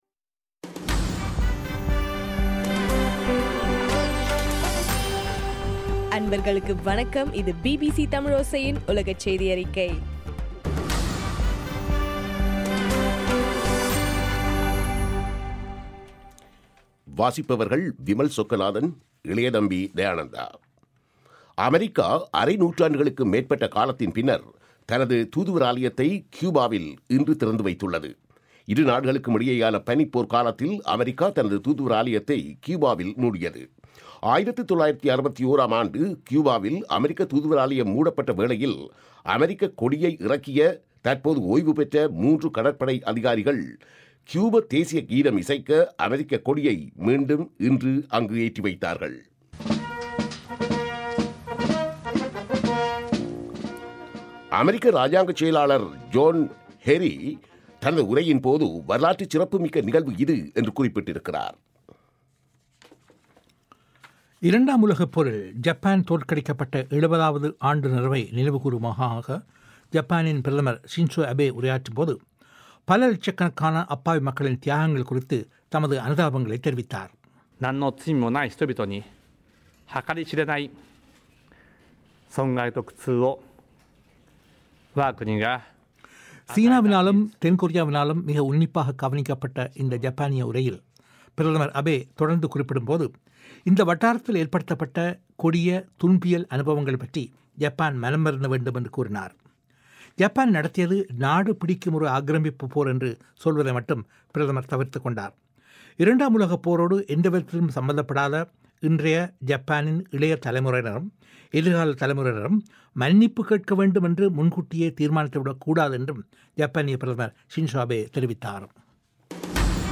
ஆகஸ்ட் 14, 2015 பிபிசி தமிழோசையின் உலகச் செய்திகள்